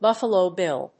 アクセントBúffalo Bíll